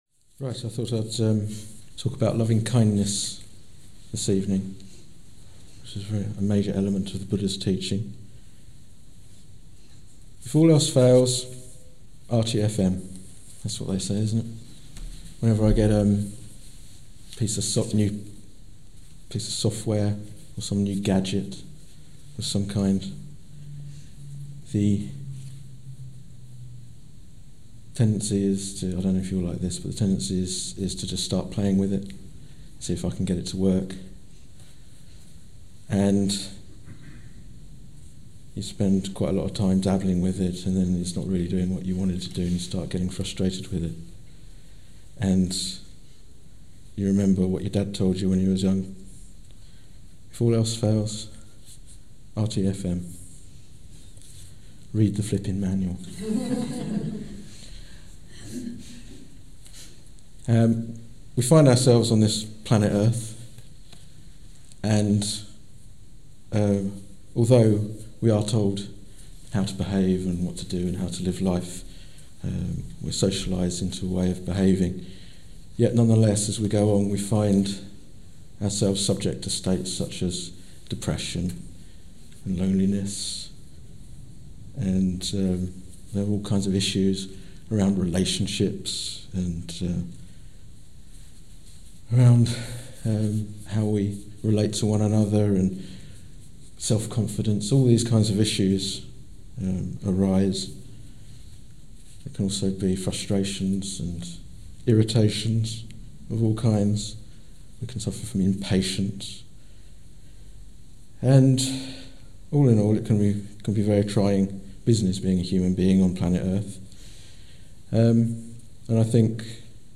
This talk was given in May 2012.